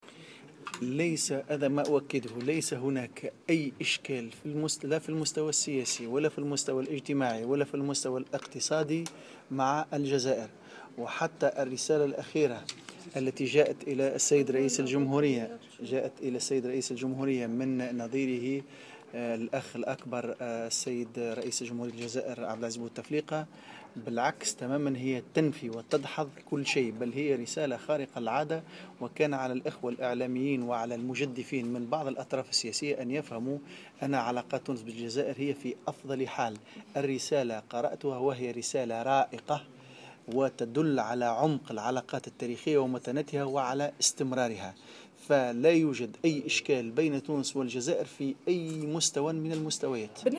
وأكد العبدولي على هامش ندوة نظمها المعهد العربي لحقوق الإنسان أنه لا وجود لأي إشكال مع الجزائر،مشيرا إلى أن الرسالة الأخيرة التي تلقاها رئيس الجمهورية الباجي قائد السبسي من نظيره الجزائري عبد العزيز بوتفليقة تدل على عمق العلاقات بين البلدين ومتانتها وتنفي ما يتم الترويج له بخصوص وجود خلافات بين البلدين.